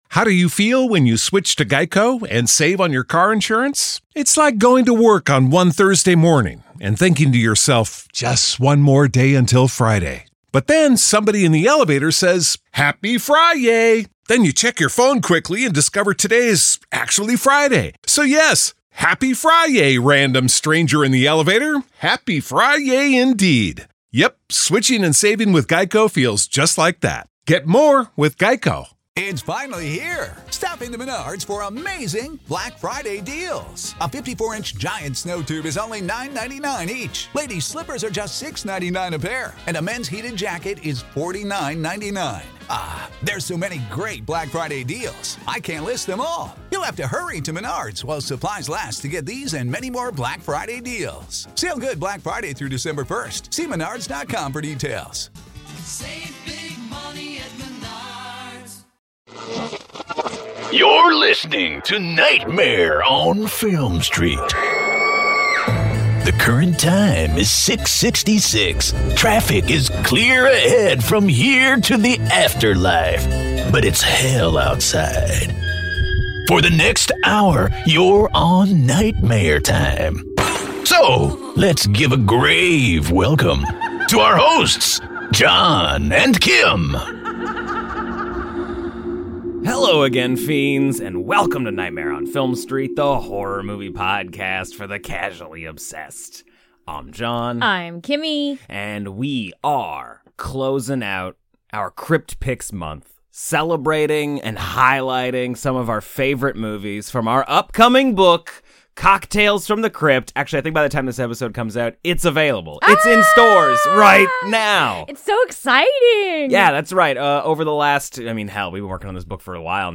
Forget the in-depth analyses and pull up a seat for lively, friendly banter that feels just like a post-movie chat with your best pals at the local bar.